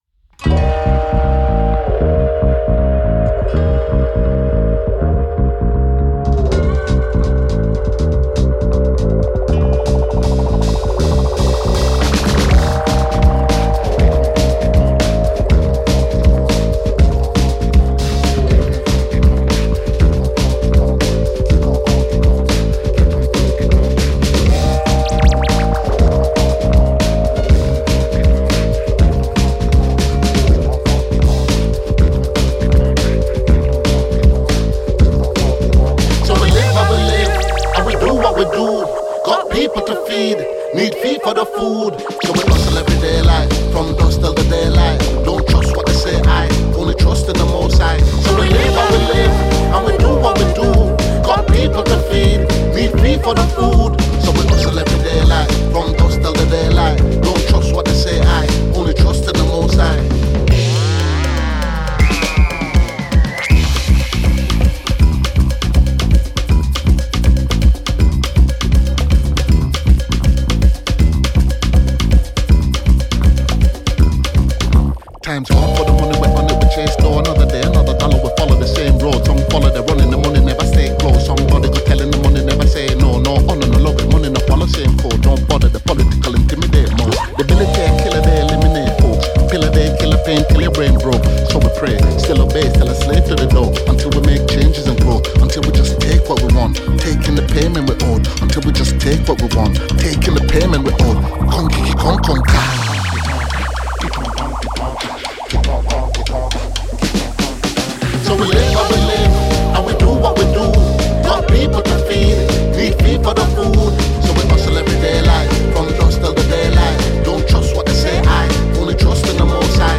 Propelled by West African rhythms and tight production